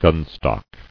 [gun·stock]